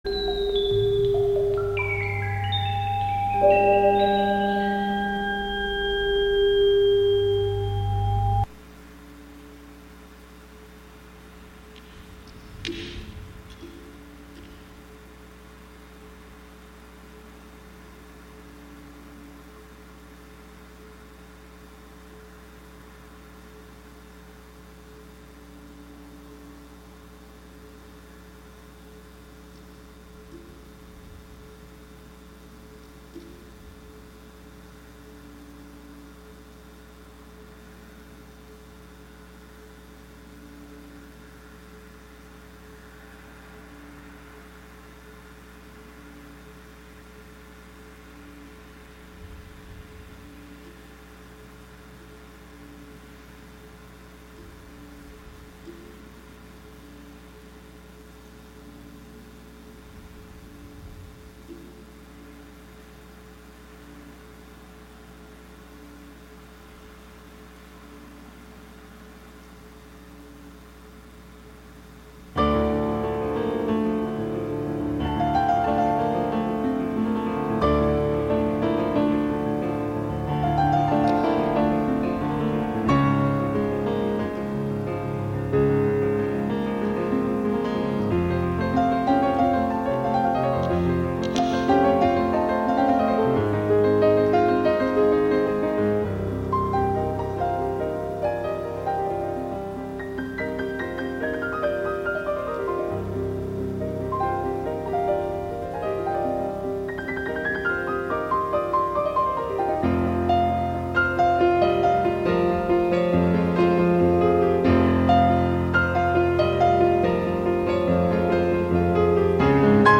plays a half hour of 1930s and 1940s music, with an occasional foray into other genres.